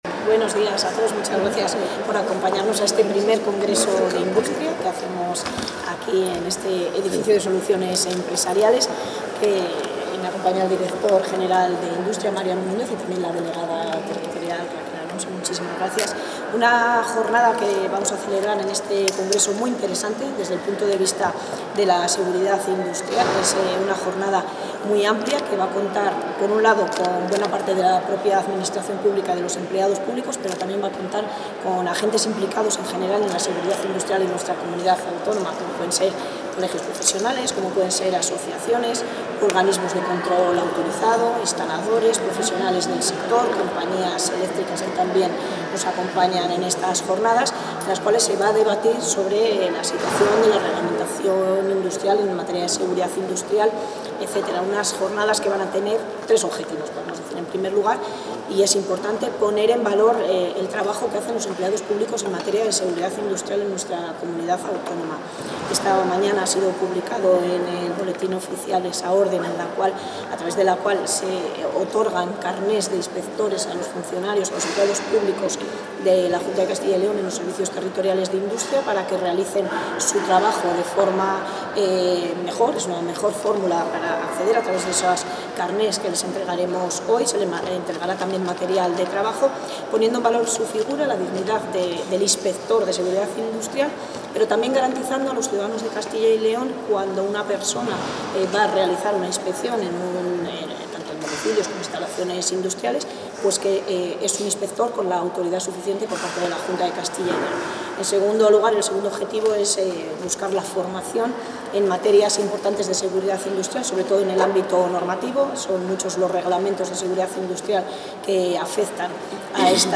La consejera de Industria, Comercio y Empleo inaugura el I Congreso de Industria de Castilla y León
Declaraciones de la consejera.